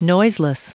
Prononciation du mot noiseless en anglais (fichier audio)
Prononciation du mot : noiseless